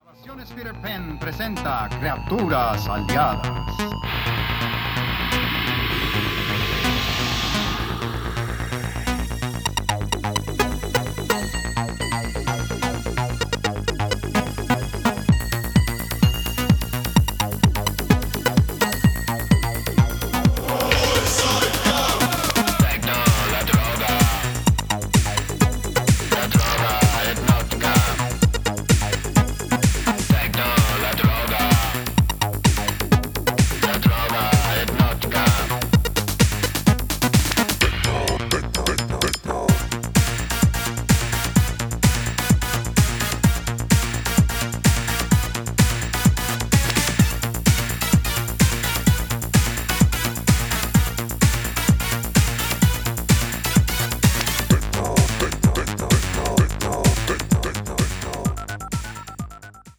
本作も御多分に洩れず80年代から地続きなゴリゴリのハードビートが鳴ってます。